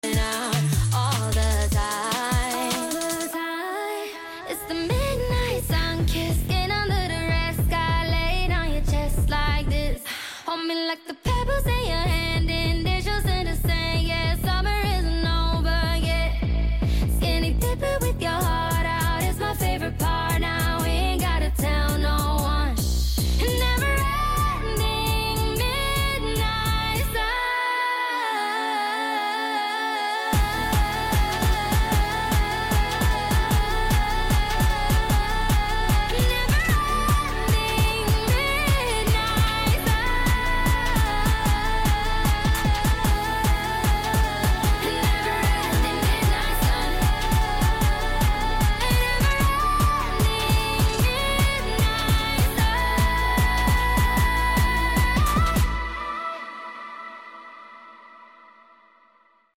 *dj Khaled Voice* ANOTHER ONE Sound Effects Free Download